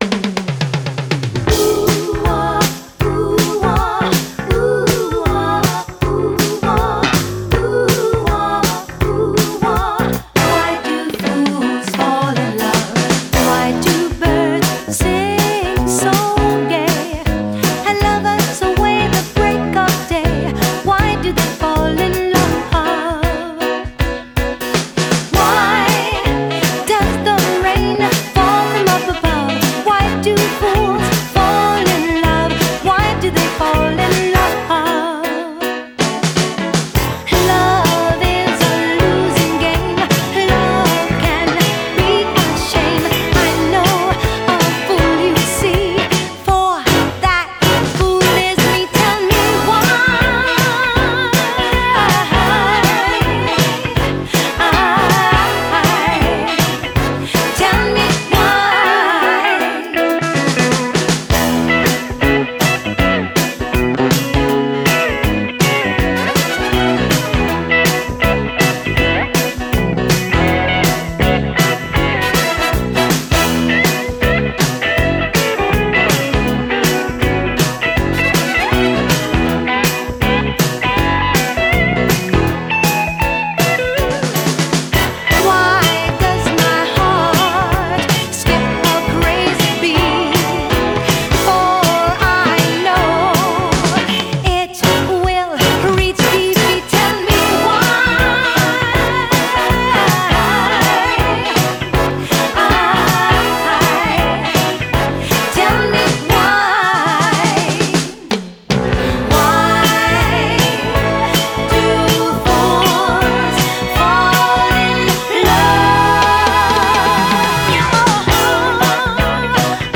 BPM160
MP3 QualityMusic Cut